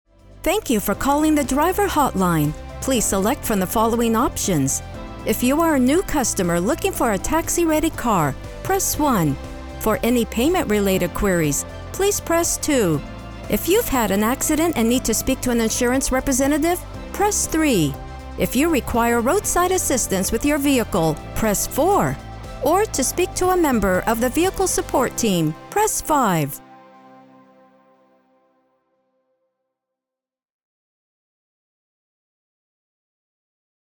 IVR / Phone Greeting
Middle Aged
My voice is said to be warm, friendly, conversational, casual.